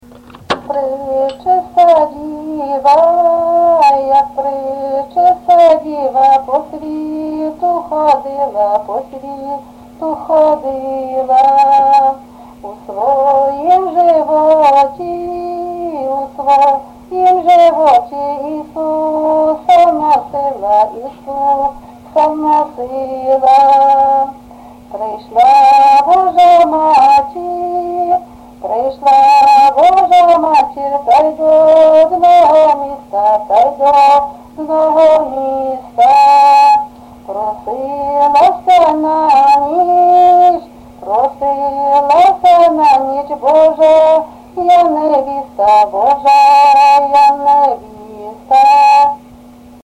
ЖанрКолядки
Місце записум. Сіверськ, Артемівський (Бахмутський) район, Донецька обл., Україна, Слобожанщина